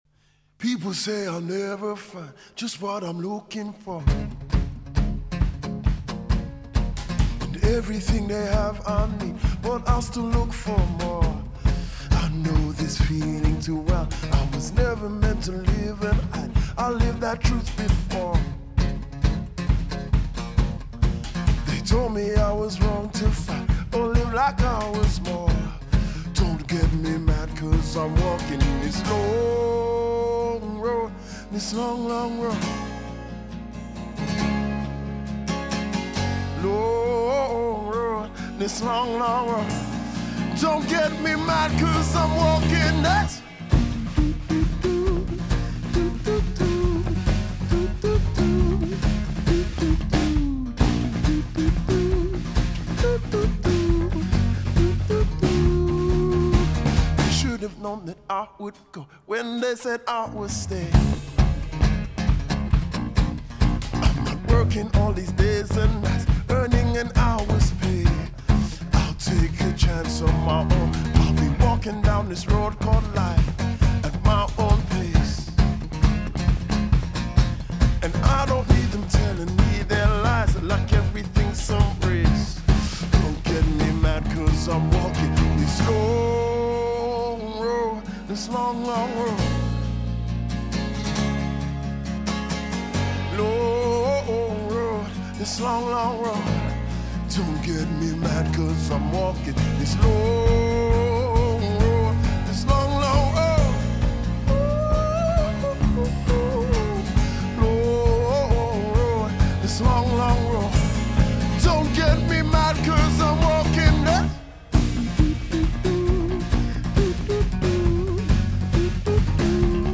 soothing sounds of heartfelt emotion. https